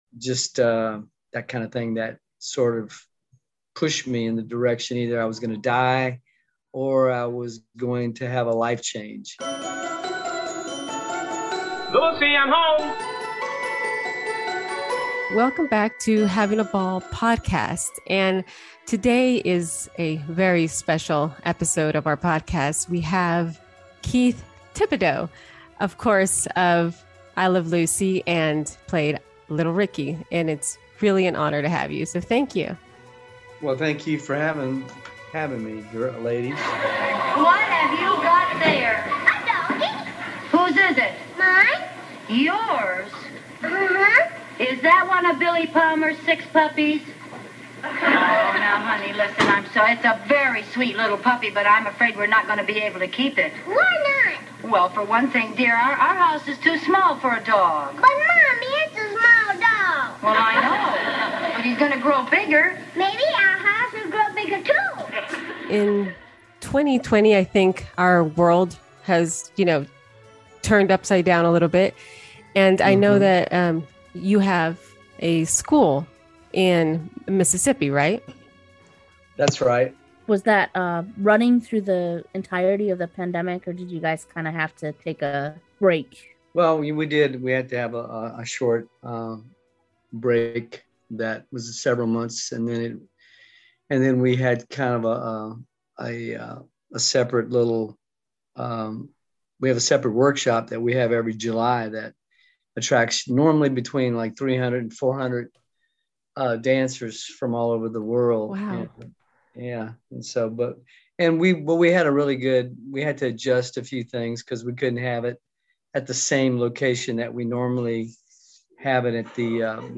The hosts chat with child actor and ‘Little Ricky’ on I Love Lucy, Keith Thibodeaux.